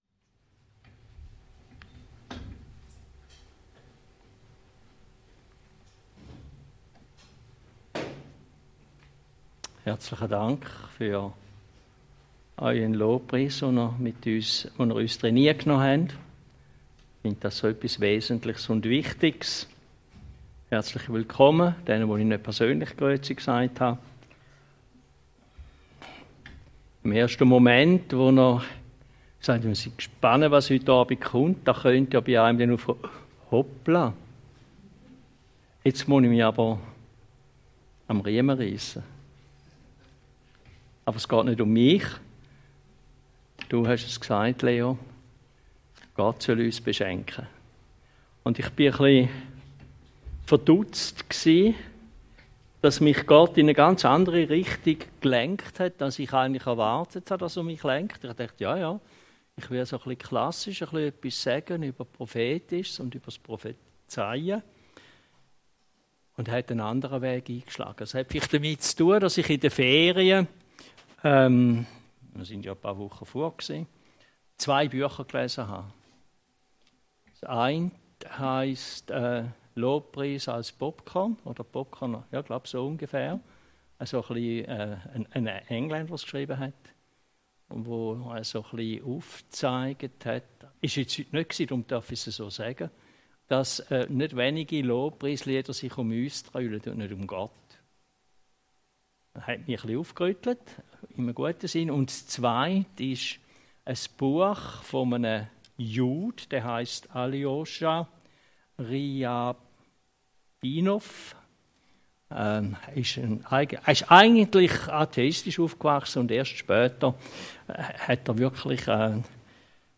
Abendgottesdienst